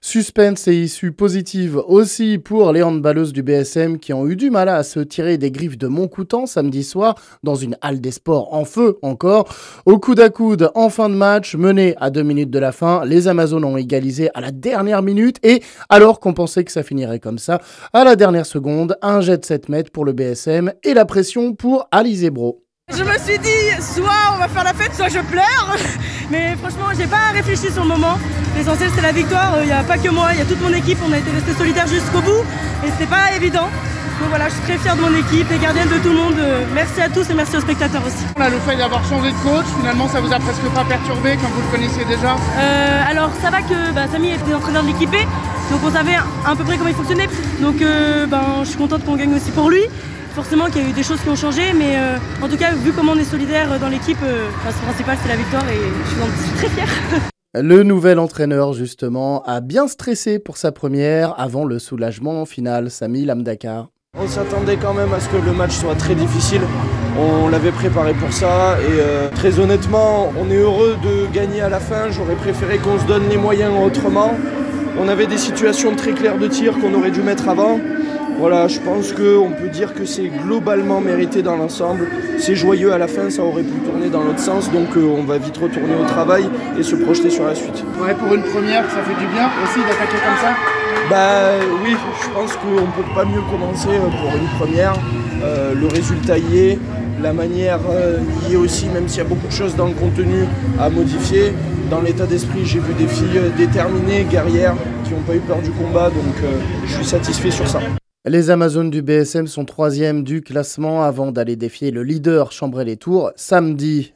Réactions